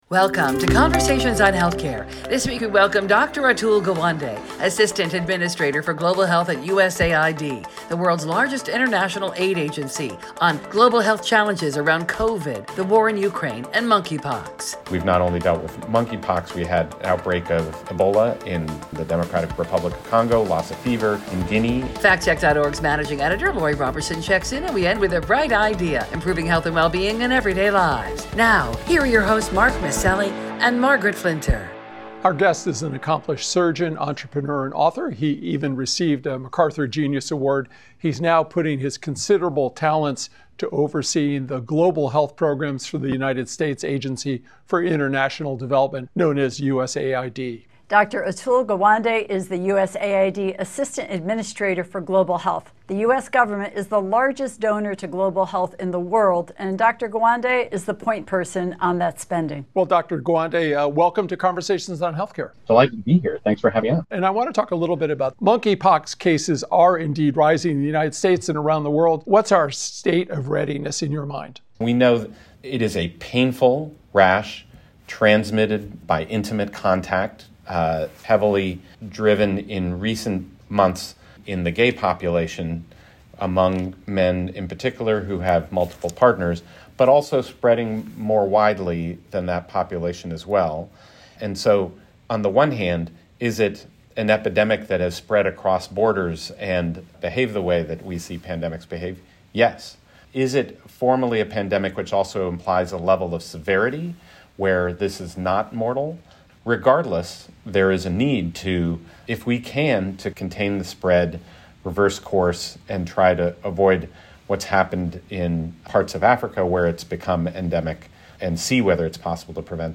Dr. Atul Gawande, Assistant Administrator for Global Health at the U.S. Agency for International Development (USAID), joins us to share how countries are responding to the U.S. Supreme Court decision overturning Roe v. Wade.